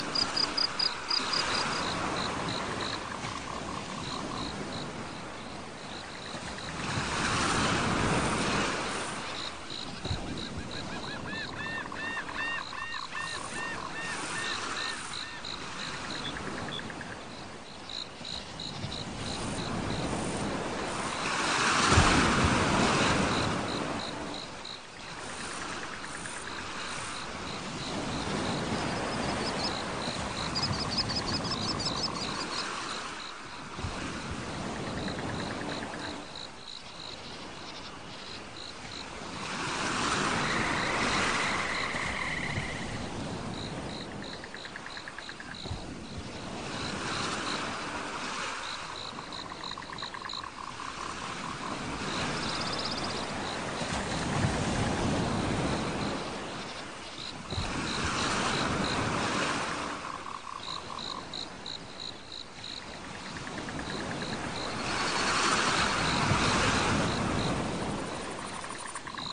Tropical Waves (1 Hour)
Nature sounds are recorded & designed to help people sleep, allowing you to relax and enjoy the sounds of nature while you rest or focus with no adverts or interruptions.
Perfect for their masking effects, they are also helpful for people suffering with tinnitus.
Tropical-Waves-Sample.mp3